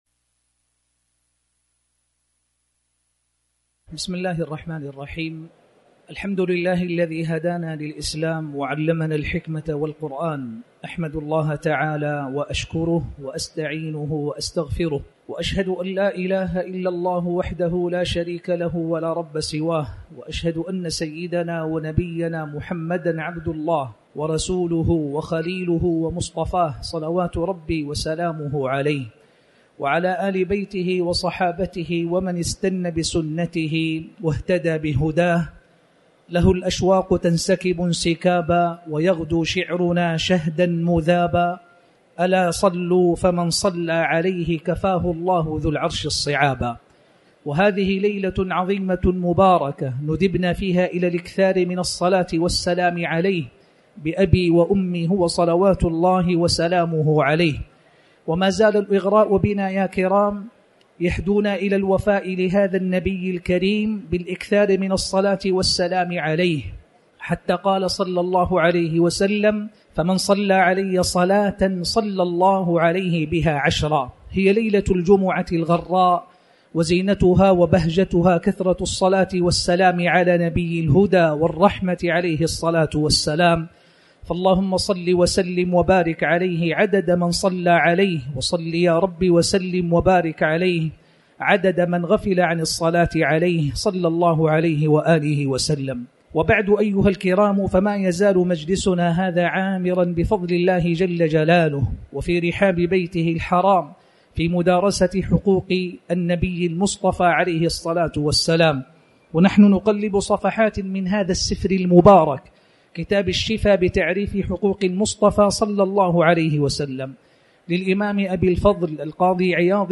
تاريخ النشر ١٥ ذو القعدة ١٤٤٠ هـ المكان: المسجد الحرام الشيخ